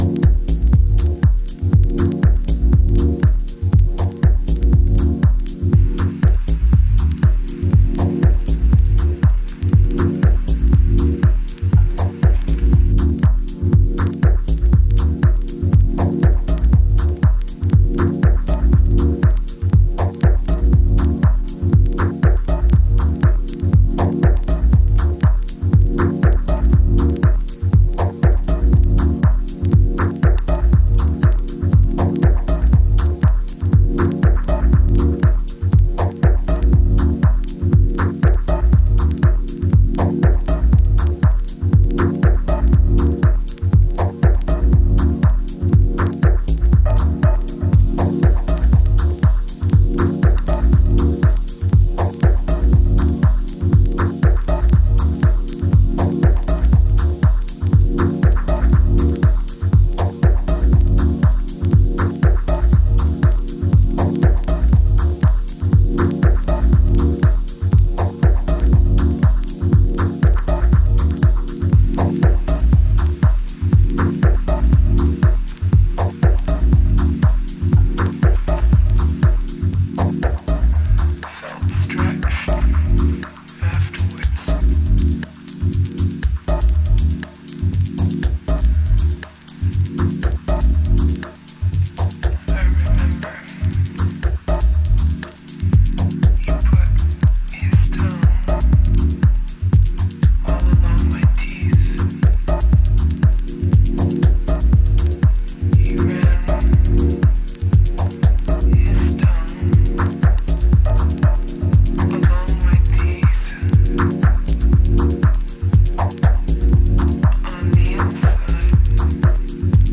パッドとノイズで空間をフローティングする
House / Techno